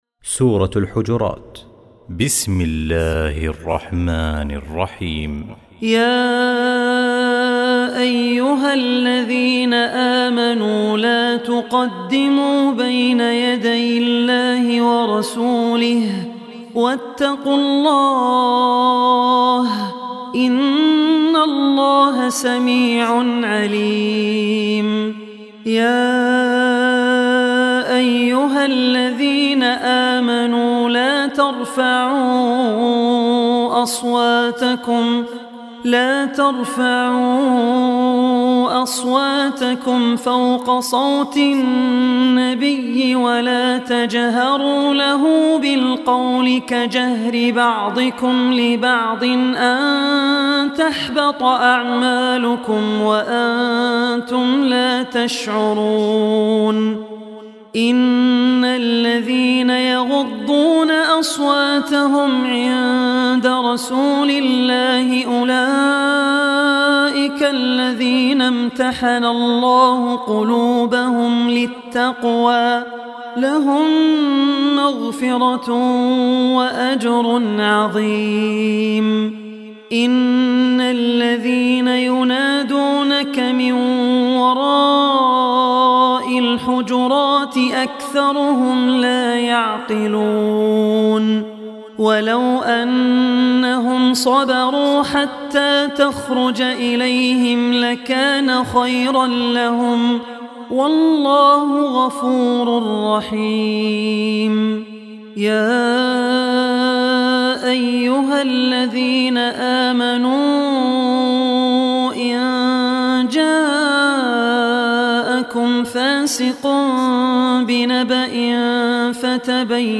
Surah Hujurat Recitation
49-surah-hujrat.mp3